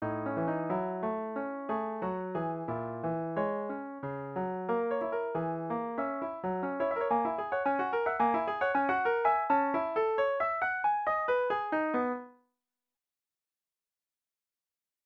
音源①は少々速すぎる感じです。